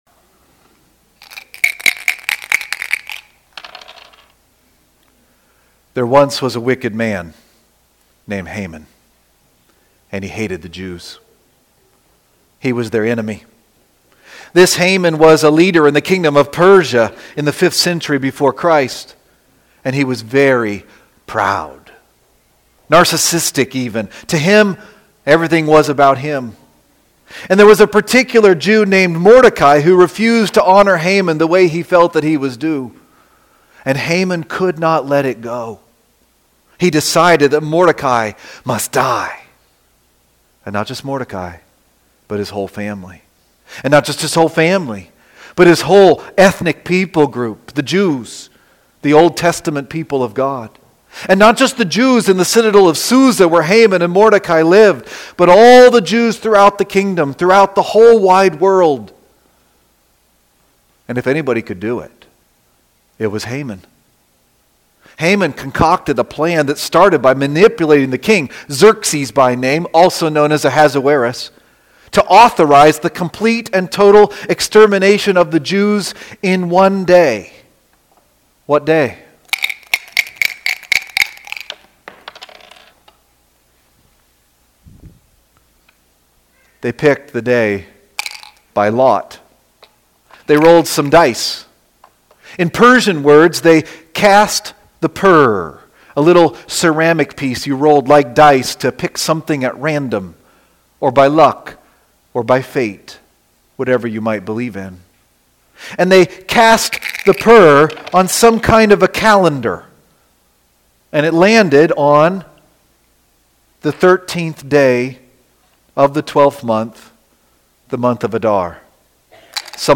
preaching on Esther 9:1-10:3.